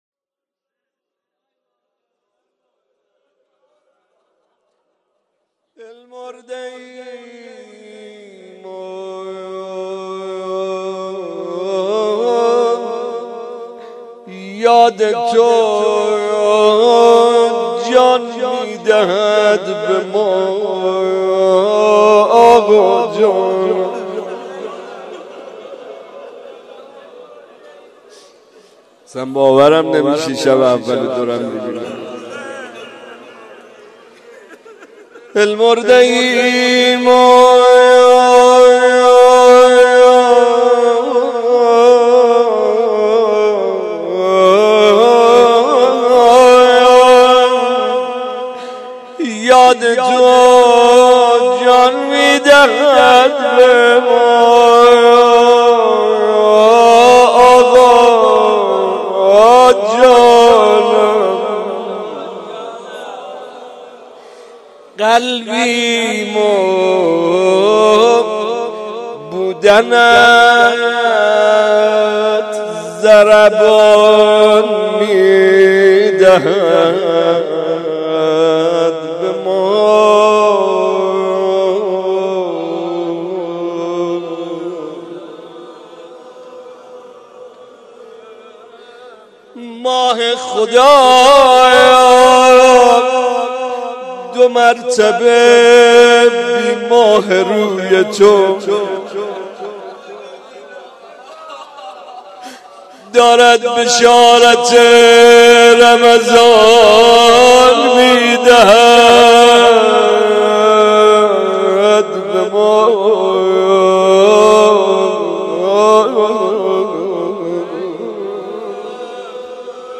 دلمرده ایم و یاد تو جان می دهد به ما | مناجات شب اول